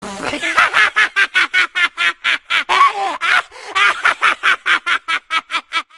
Risada de Eric Cartman do desenho/série South Park.
risada-cartman-south-park.mp3